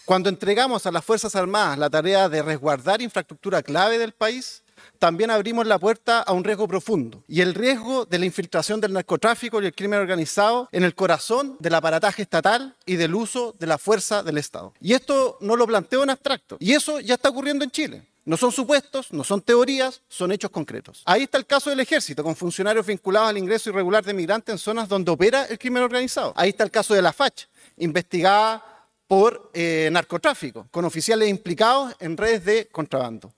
Desde el otro extremo, el diputado Matías Ramírez (PC) alertó sobre los riesgos de utilizar militares en estas tareas.